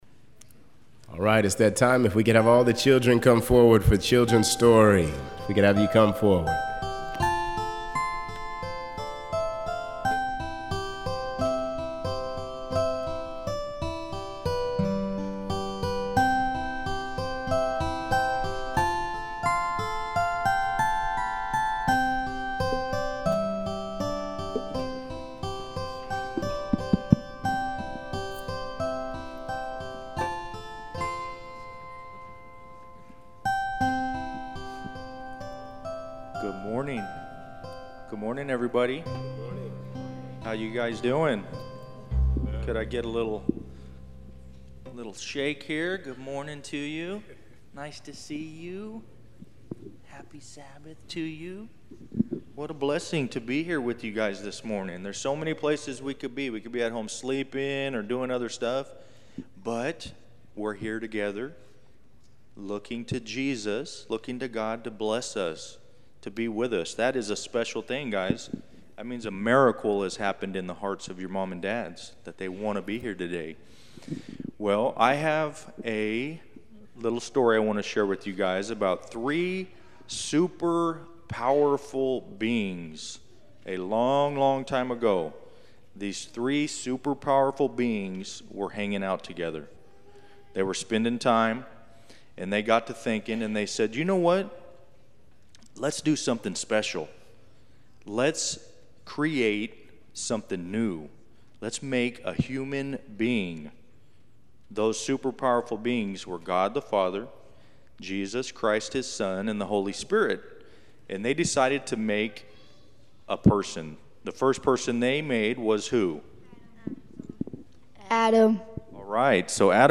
Children Story